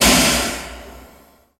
Airlock Door Open, Burst Of Steam With Hiss Fade Out